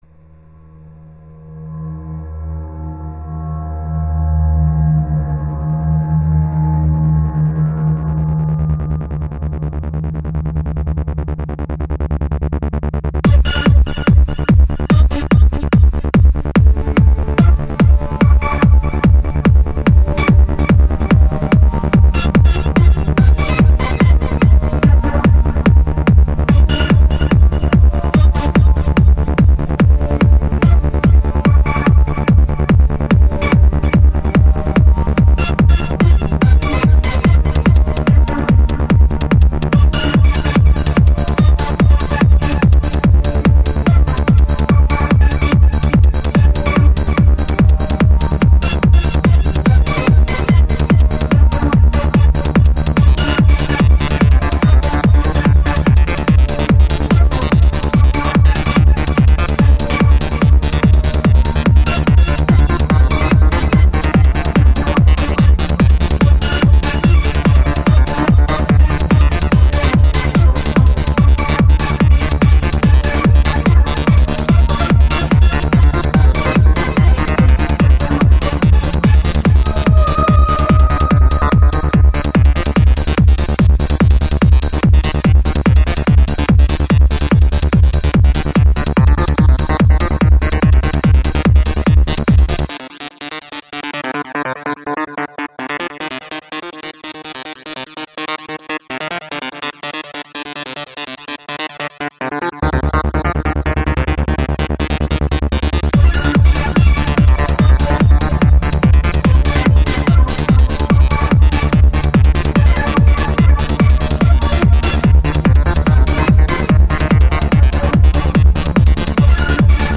Psy stuff[mp3]
You might want to turn up the aggression if you use it for a C&C game.